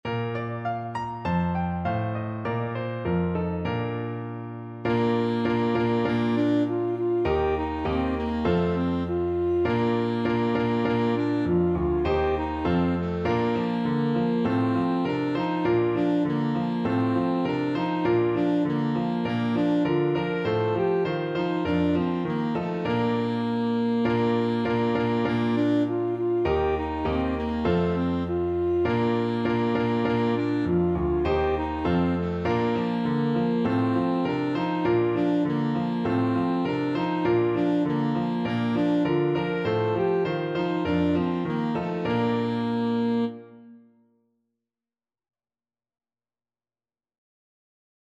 Alto Saxophone
Bb major (Sounding Pitch) G major (Alto Saxophone in Eb) (View more Bb major Music for Saxophone )
Two in a bar =c.100
2/2 (View more 2/2 Music)
Traditional (View more Traditional Saxophone Music)